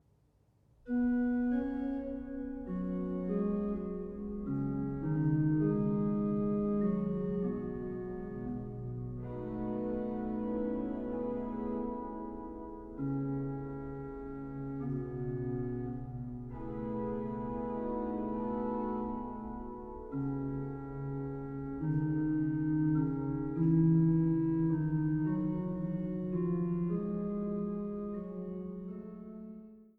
Jehmlich-Orgel in der Kirche St. Wolfgang zu Schneeberg